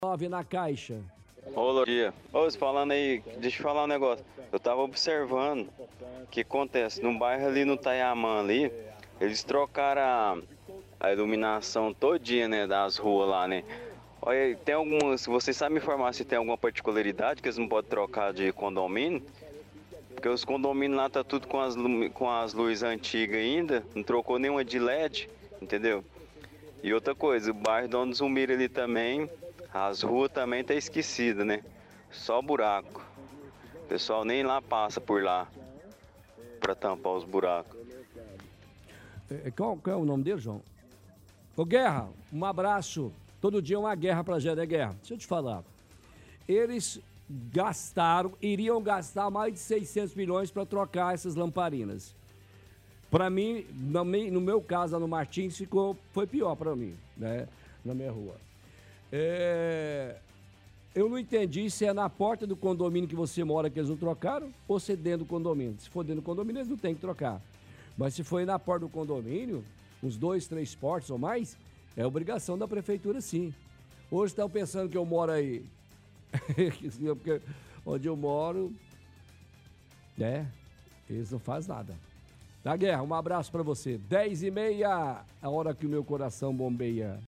– Ouvinte: No bairro Taiaman trocaram toda a iluminação para lâmpada led, mas não trocaram dos condomínios, todos ainda estão com luzes antigas.